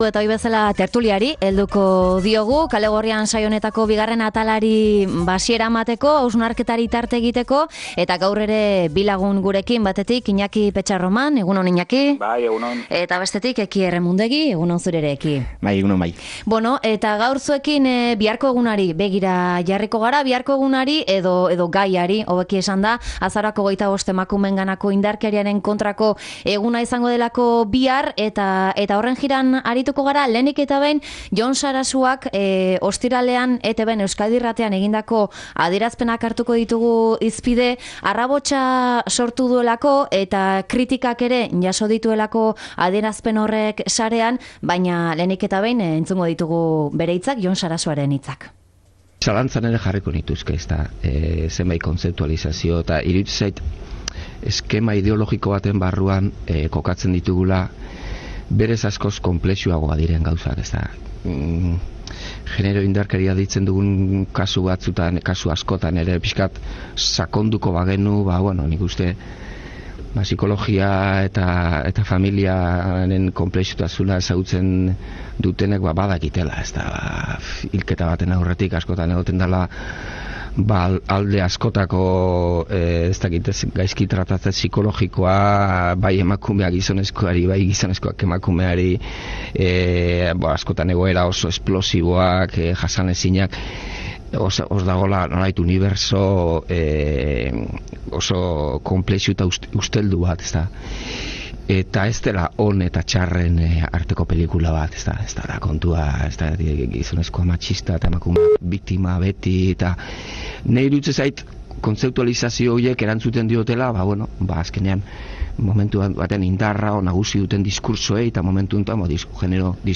Tertulia: genero indarkeria, pasio krimenak?